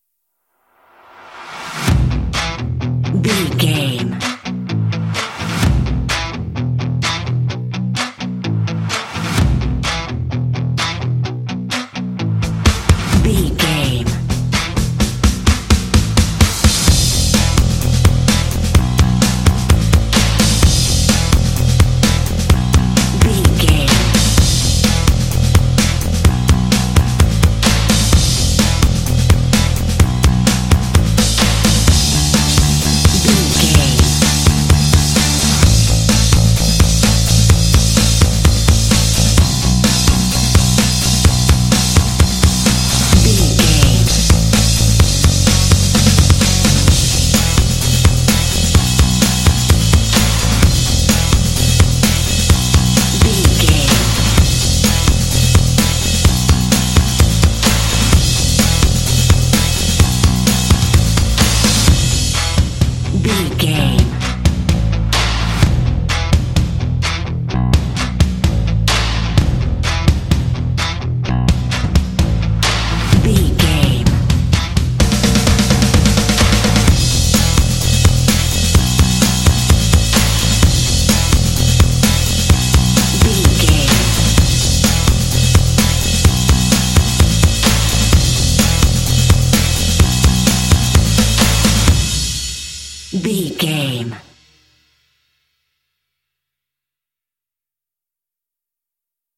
This indie track contains vocal “hey” shots.
Ionian/Major
lively
cheerful/happy
drums
bass guitar
percussion
synth-pop
alternative rock